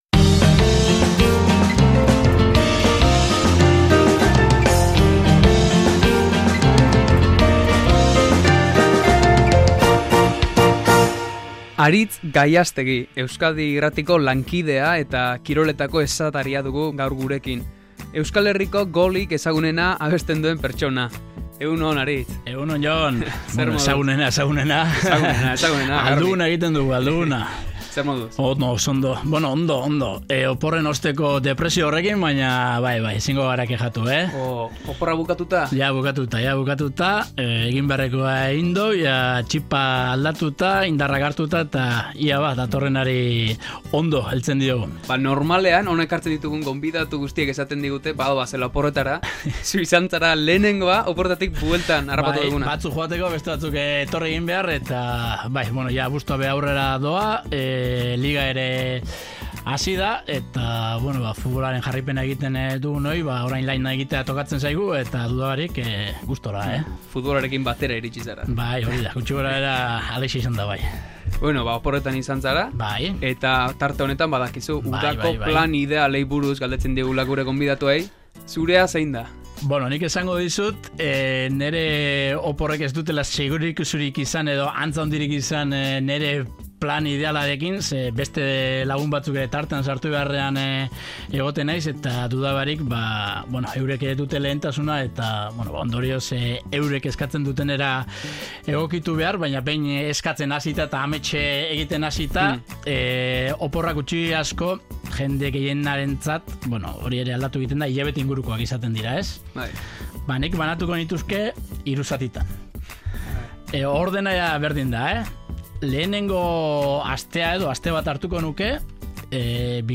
elkarrizketatu du udako planen tartean.